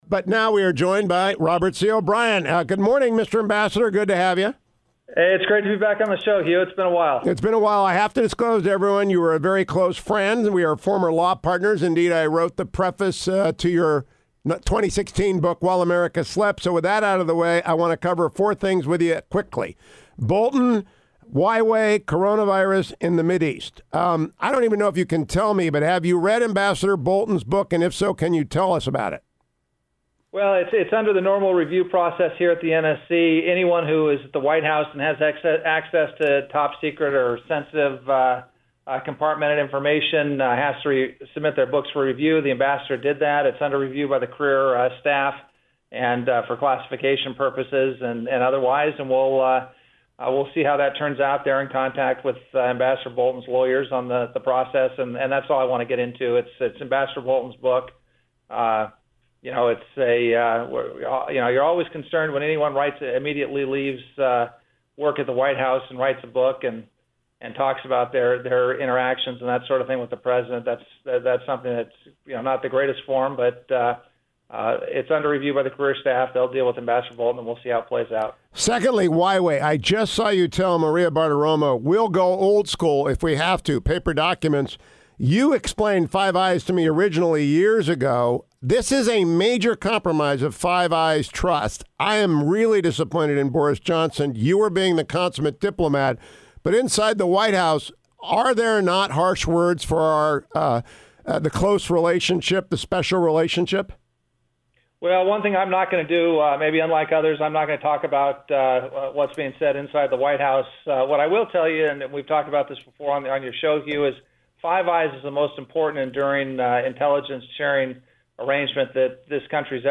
the National Security Advsior, Robert C. O’Brien, joined me this morning:
End of interview.